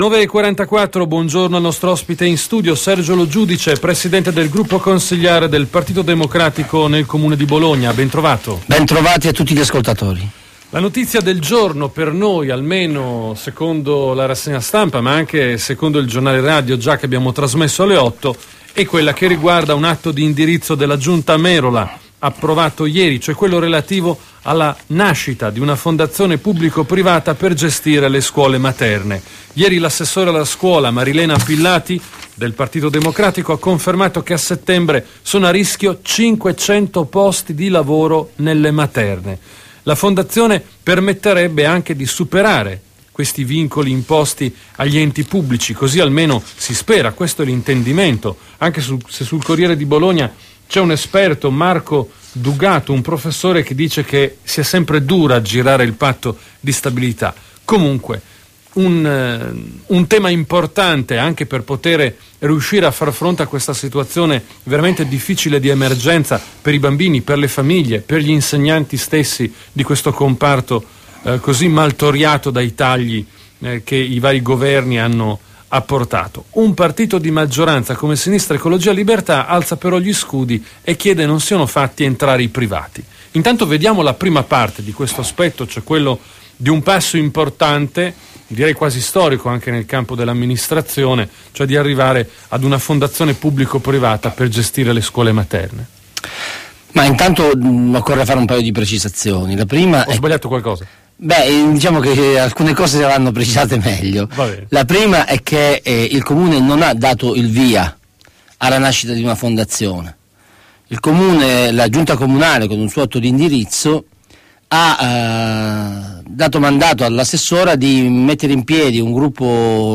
Attualit� politica: il capogruppo Pd Sergio Lo Giudice in un'intervista a Radio Tau - 24 aprile 2012